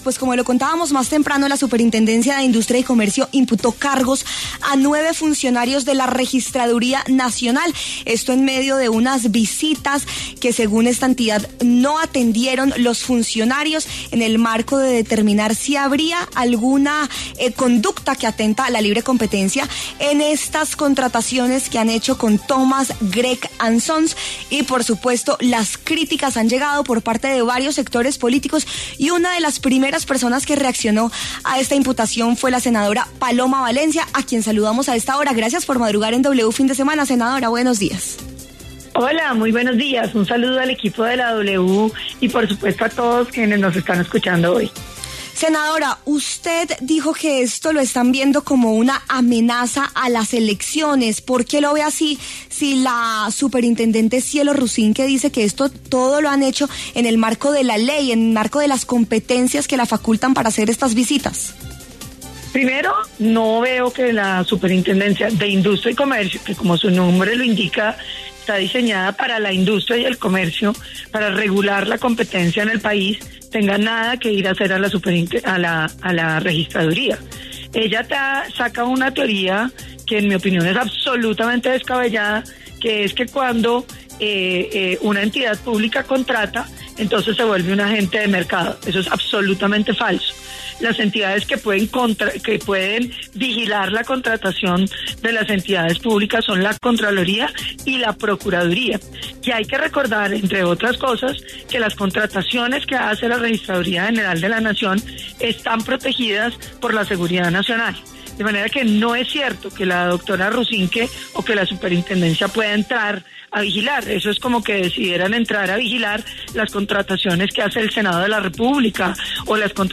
La senadora del Centro Democrático Paloma Valencia pasó por los micrófonos de W Fin De Semana para hablar sobre la imputación de cargos que hizo la Superintendencia de Industria y Comercio a funcionarios de la Registraduría.
Reviva la entrevista con la senadora Paloma Valencia a continuación